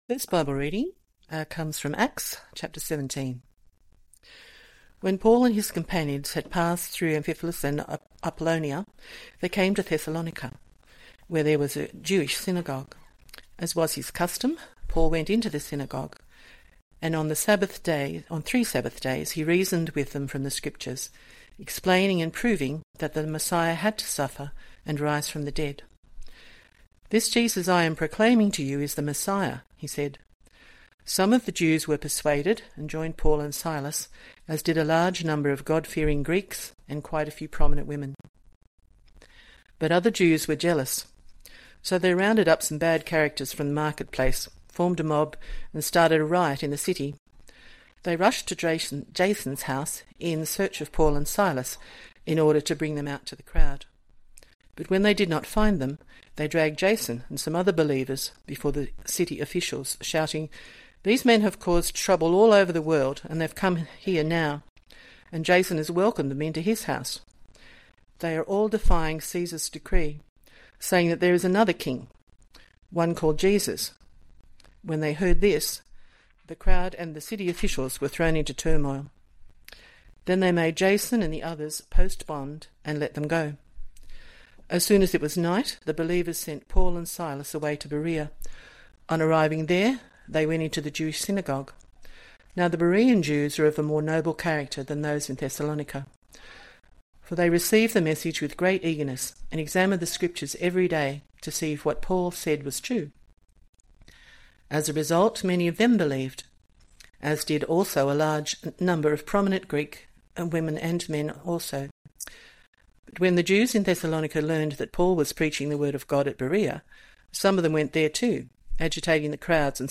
Bible Talks | Bairnsdale Baptist Church